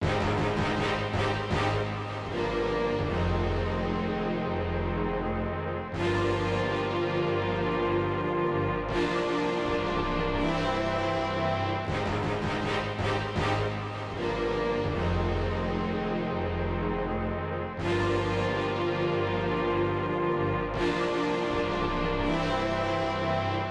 06 symphony A.wav